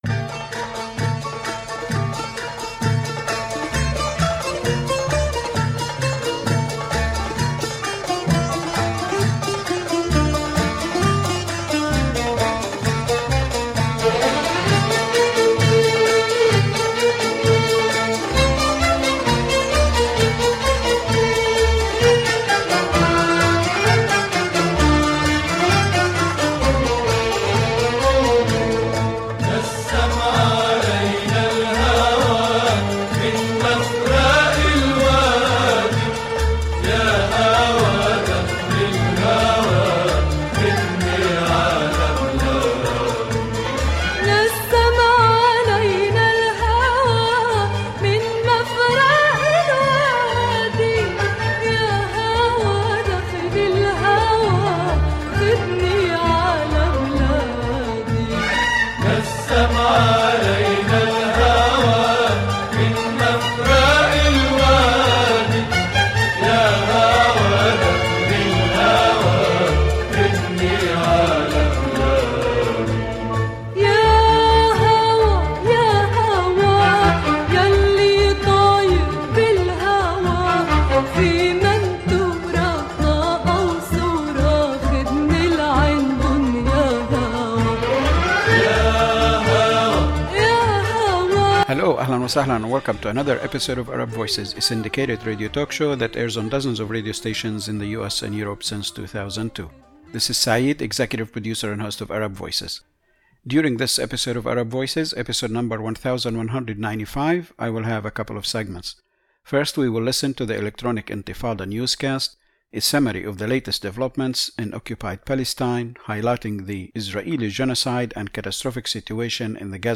Syndicated radio talk show since 2002 that airs on several radio stations in different cities in the U.S.A. & Europe. Arab Voices is an independent program that aims at bringing the truth, facts, and realities to a wide and diverse range of listeners.